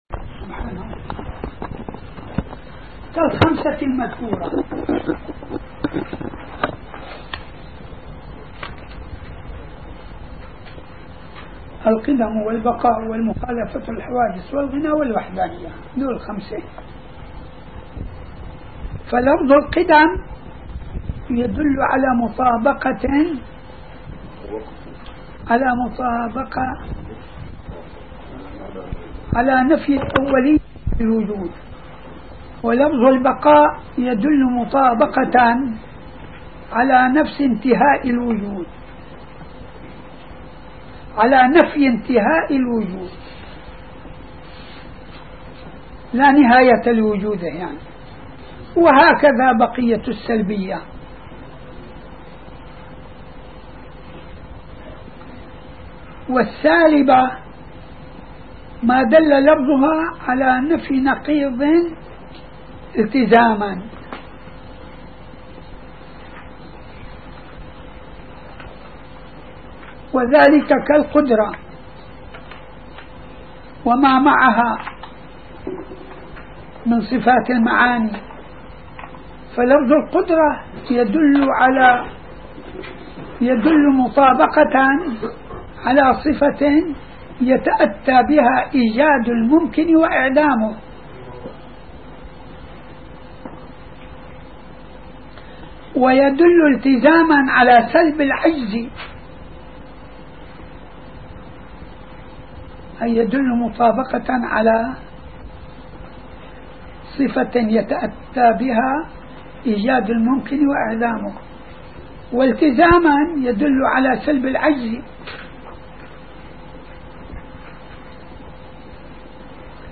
الدرس السابع: من الصفحة 77 إلى الصفحة 86